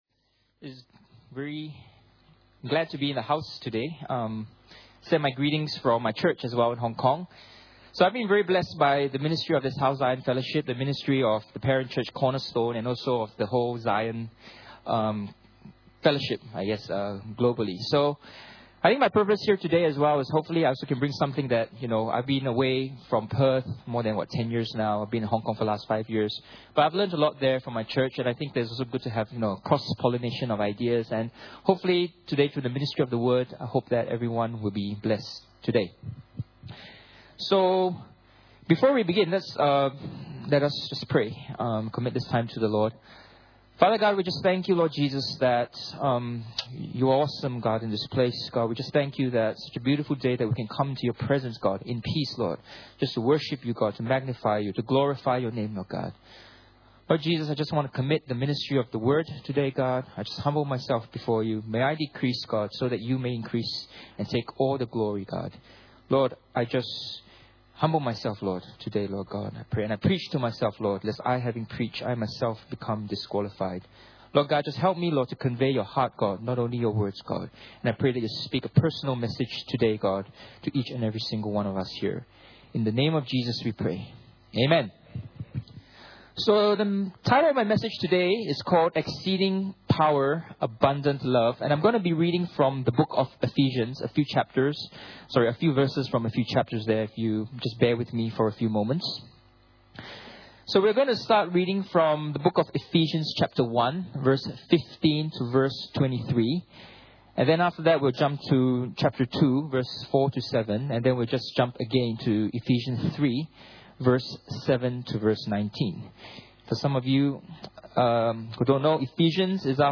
Guest Speaker Service Type: Sunday Morning « The Wise Will Seek and Receive the Lord Jesus New Year’s Message